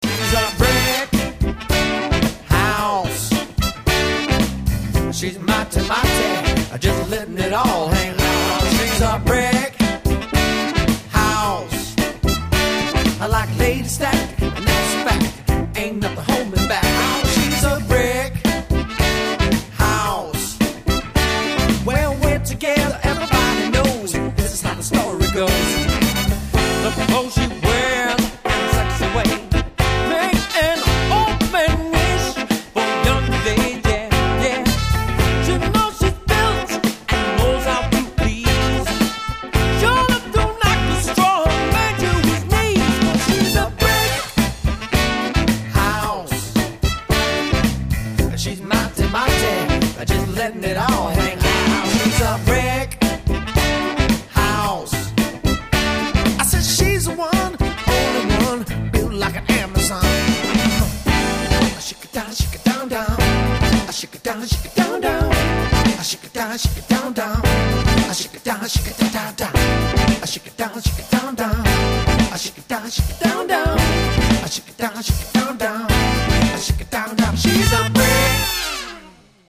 Disco & Funk